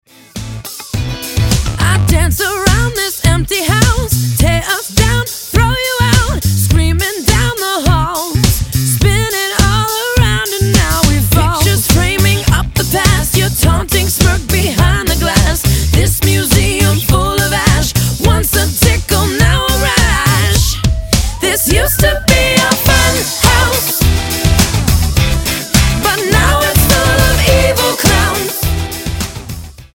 в mp3 нарезке на звонок телефона